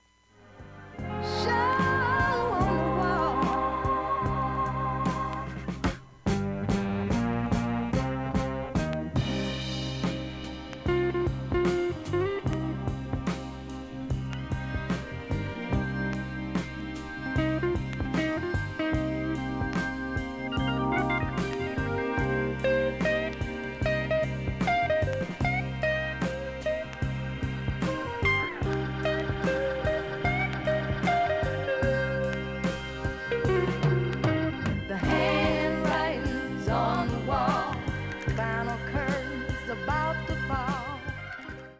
音質良好全曲試聴済み。
かすかな周回プツが出ますが
かすかに6回と4回程度しか聴こえないレベルです。